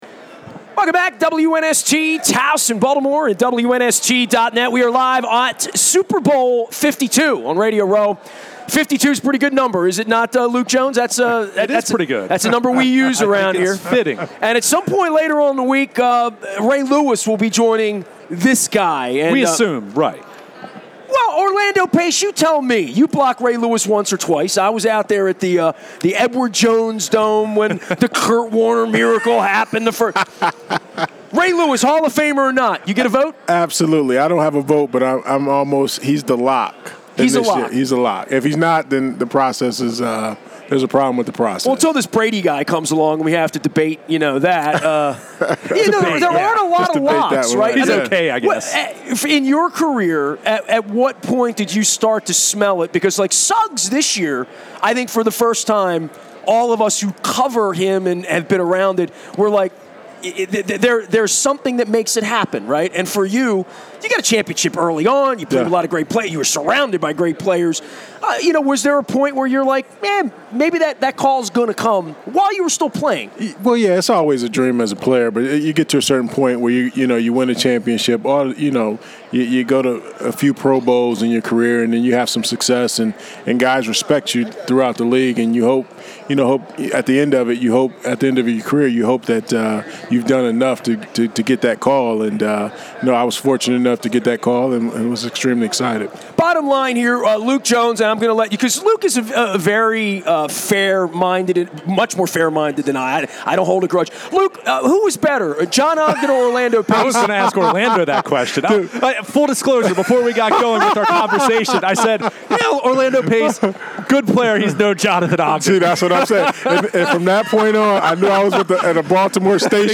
on Radio Row in Minnesota for Super Bowl 52